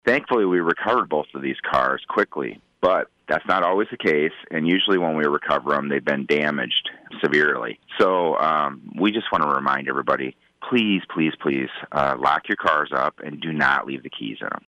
He joins KFJB earlier this week to discuss the uptick.